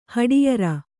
♪ haḍiyara